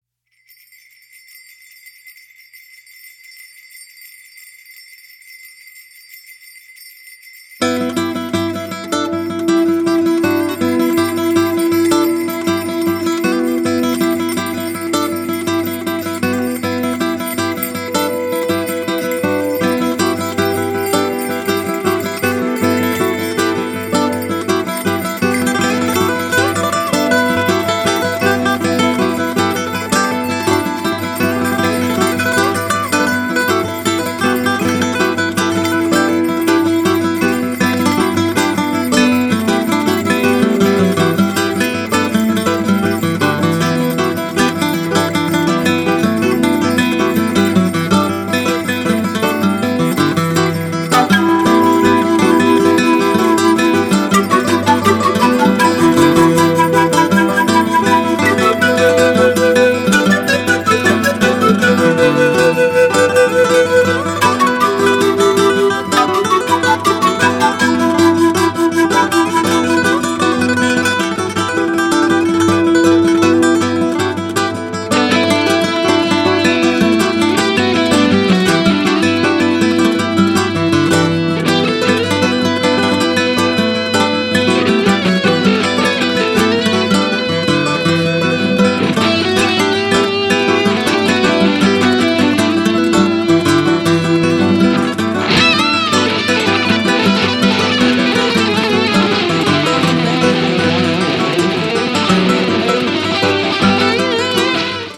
かなりプログレッブな感覚が横溢した作品となっていますね！
ロックのエッセンスがかなり出ていて
濃厚な演奏を楽しめます！